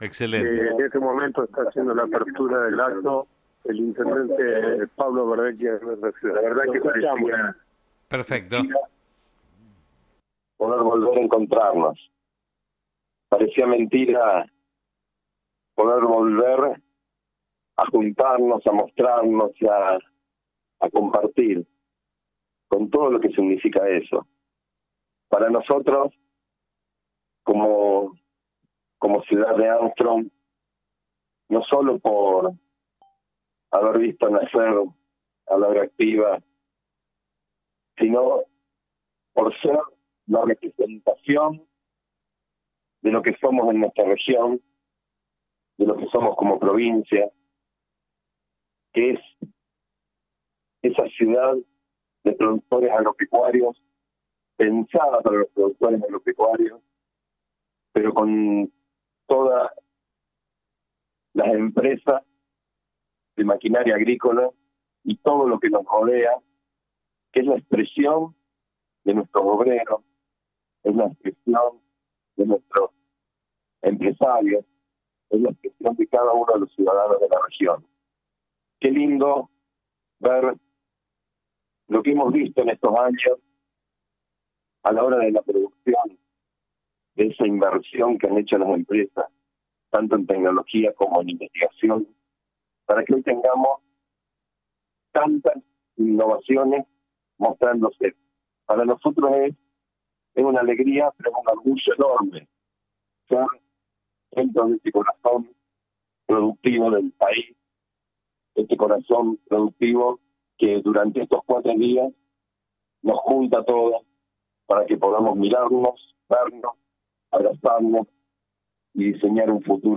La inauguración de la 28 edición de AgroActiva convocó emociones, recuerdos, abrazos, encuentros y reencuentros frente al pórtico de entrada de la megamuestra.
Pablo Verdecchia Intendente de Armstrong
Intendente-Pablo-Verdecchia-en-apertura-de-Agroactiva-2022.mp3